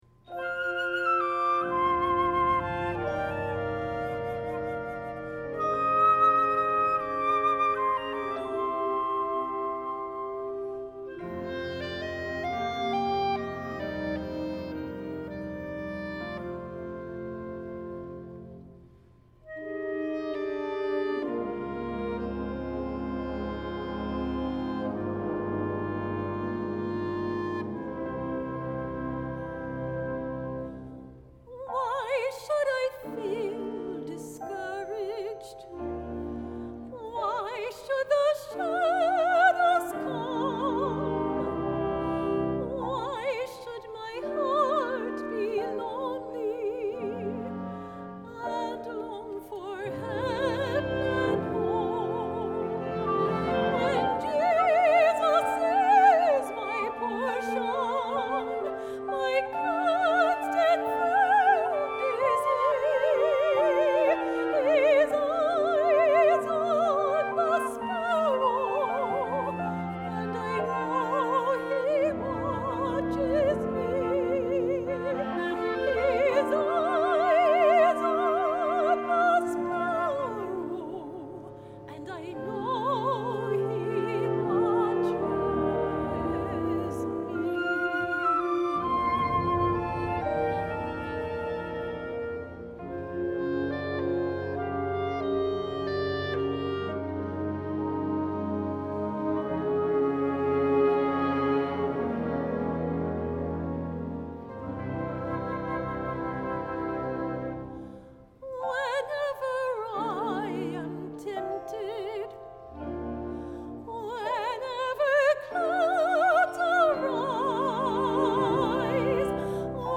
Genre: Solo Soprano with Wind Orchestra
Soprano Solo
Harp or Piano (opt.)
Percussion (Tam-Tam, Triangle, Suspended Cymbal)